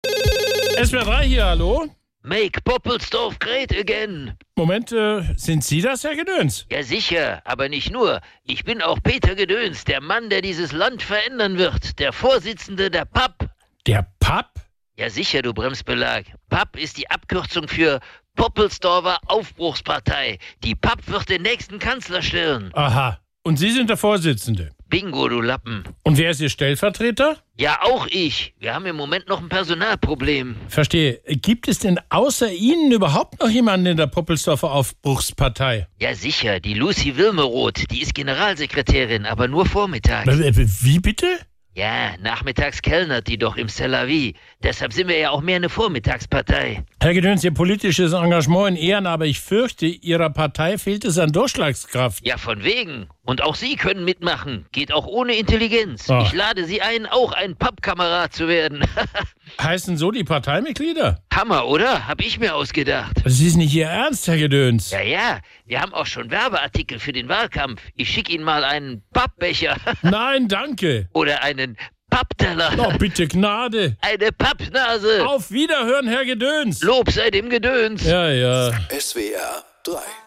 SWR3 Comedy Peter Gedöns: PAP-Partei